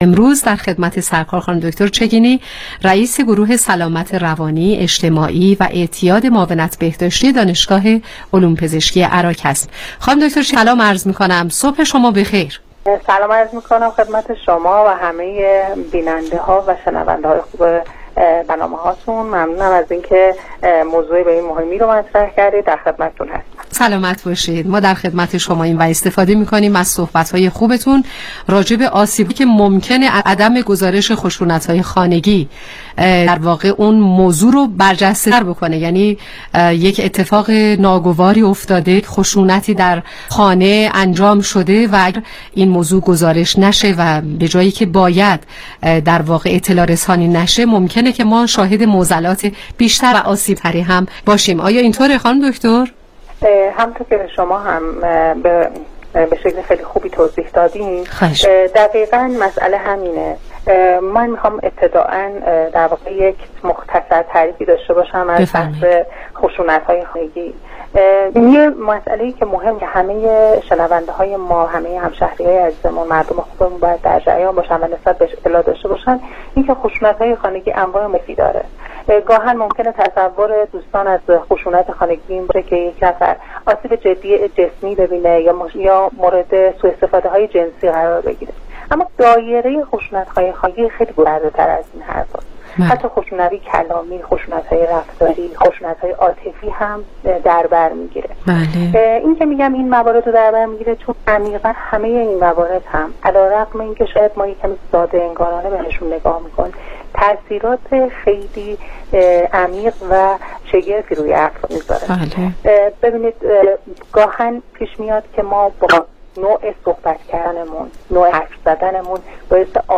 برنامه رادیویی کانون مهر